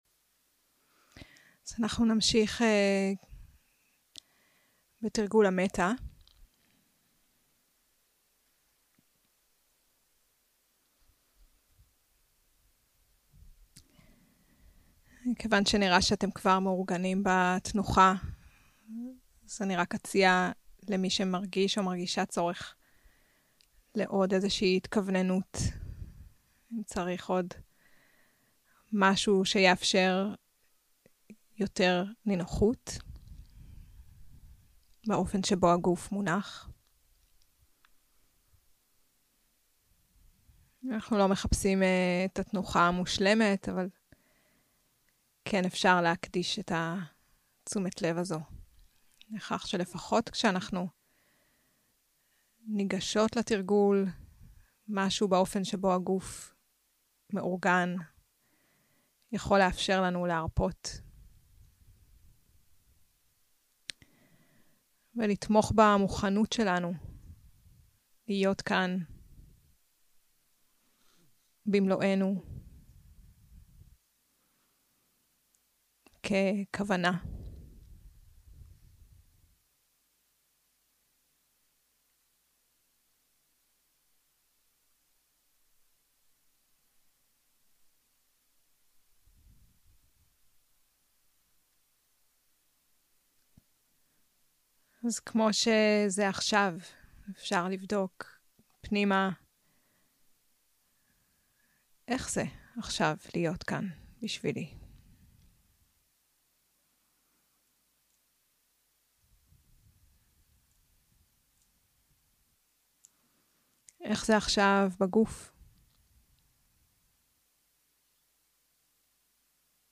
סוג ההקלטה: מדיטציה מונחית שפת ההקלטה